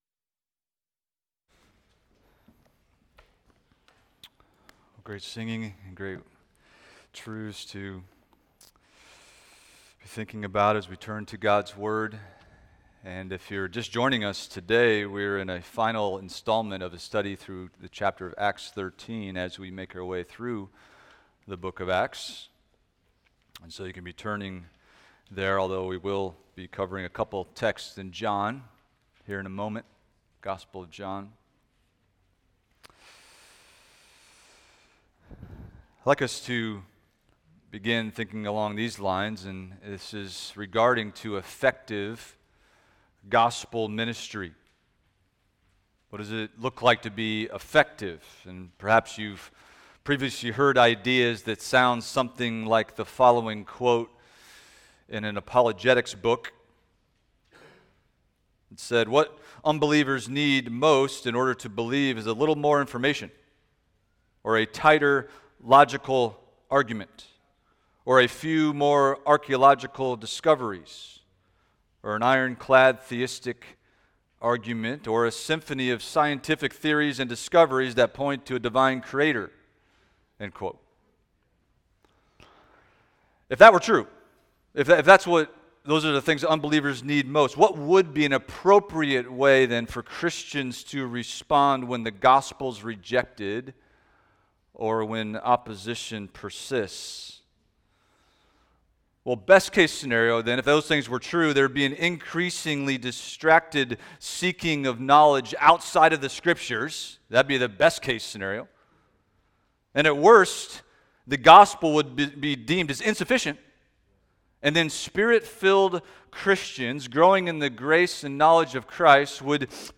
Expository Preaching from First Peter – 1 Peter 1:6-9 Rejoicing Greatly in Hope